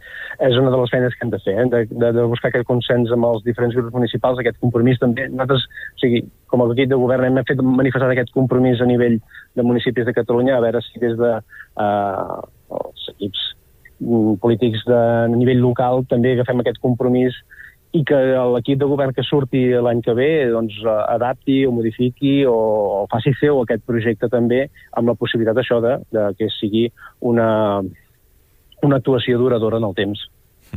Per això ens va visitar Marc Heras, regidor de Medi Ambient de Palafrugell, al Supermatí.